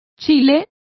Complete with pronunciation of the translation of chili.